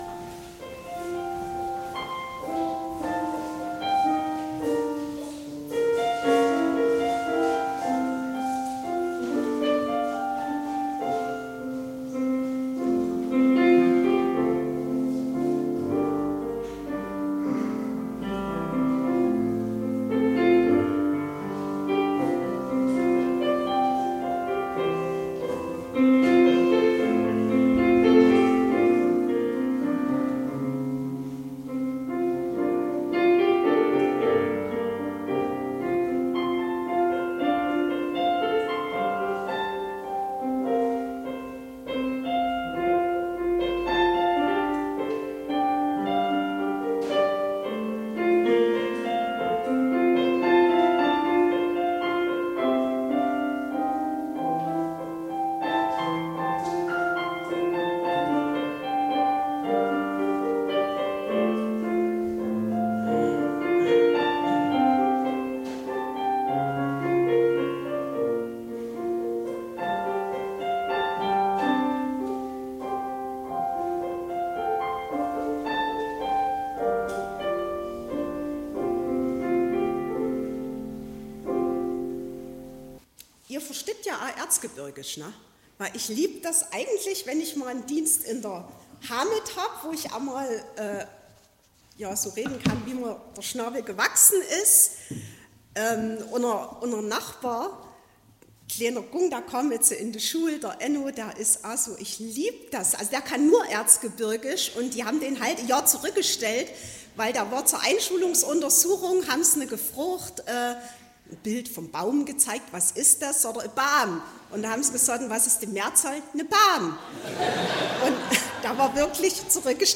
11.08.2024 – Gottesdienst
Predigt und Aufzeichnungen